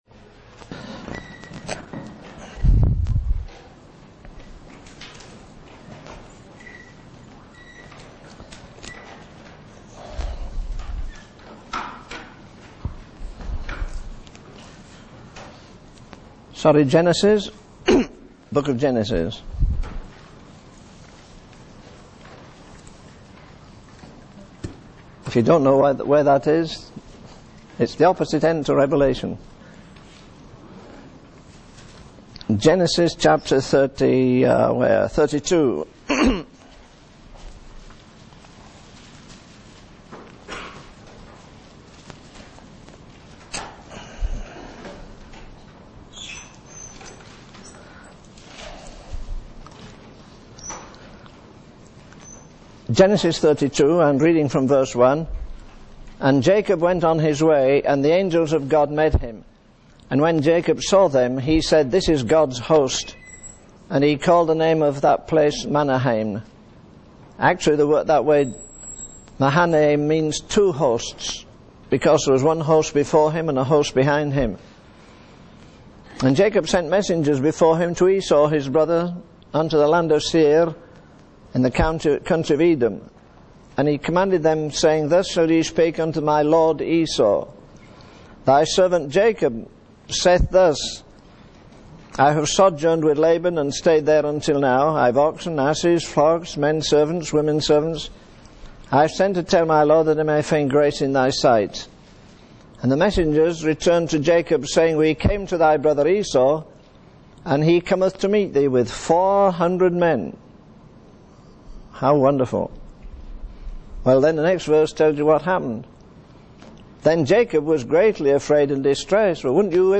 In this sermon, the preacher emphasizes the importance of stirring oneself up in the faith. He references the story of Charles Wesley's hymn, 'Come Thou Traveler Unknown,' to illustrate the need to constantly seek God's presence. The preacher also highlights the issue of alcoholism among Native Americans and criticizes the complacency of churchgoers.